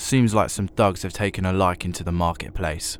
Voice Lines
Update Voice Overs for Amplification & Normalisation